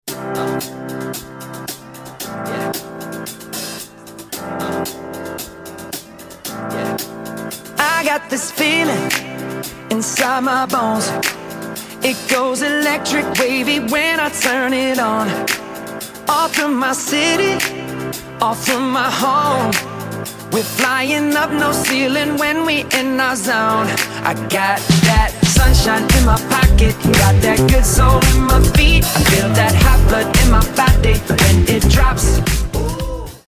• Качество: 320, Stereo
поп
мужской вокал
веселые
заводные
dance